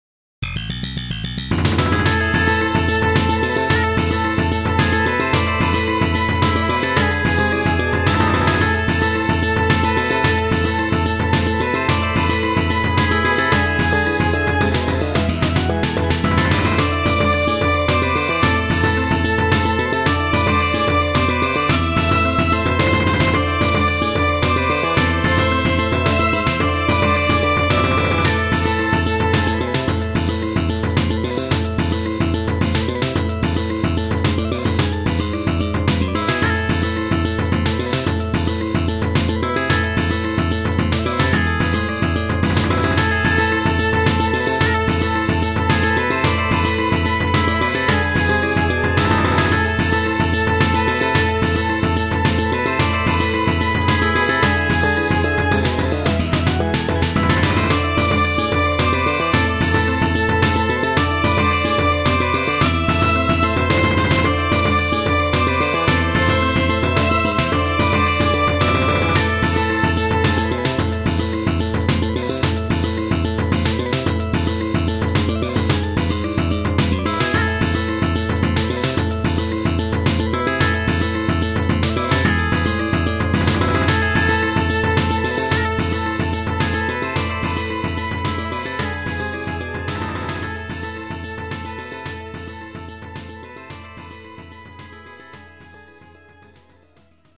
トラップフル稼働っぽい迷宮系、偽エスニック風味。
1ループフェードアウト。